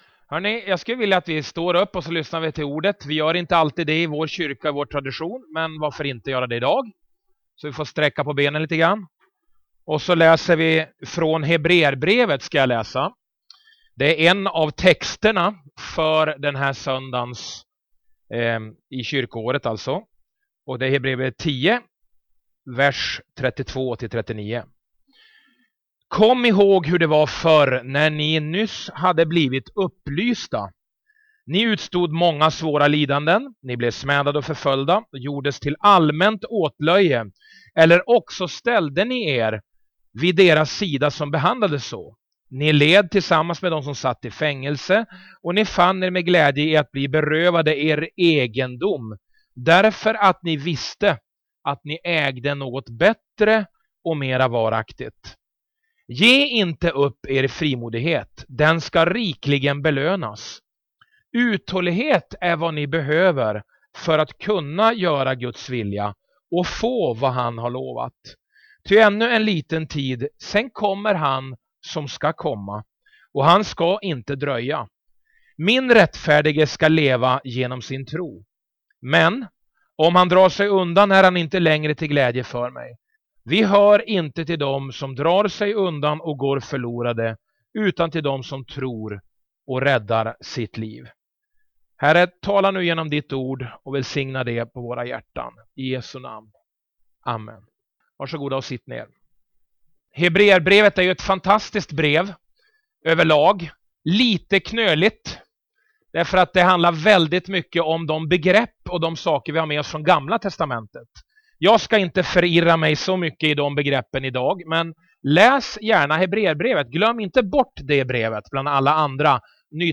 Predikningar